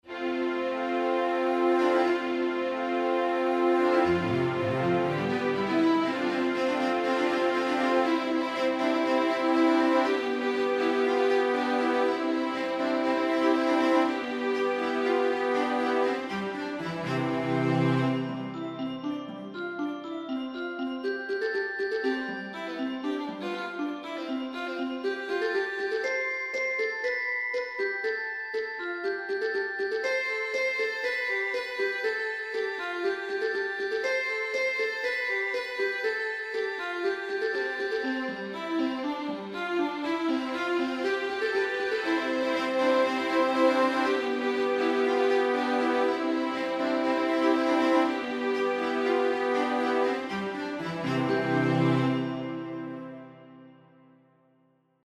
Une découverte du nouveau monde plutôt ludique et émerveillée, le rendu n'est pas ultra convaincant mais c'est original.
Sympa, ça sonne un peu nouveau monde champêtres, petits animaux et compagnie, c'est sympa comme ambiance :)
Très joyeux comme thème :]
C'est vrai que les clochettes font pensé a un monde ou tout est rose, et ou on voit des lapins qui te parlent :p c'est assez marrant